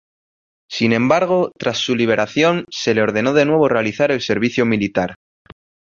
Uitgespreek as (IPA)
/ˈtɾas/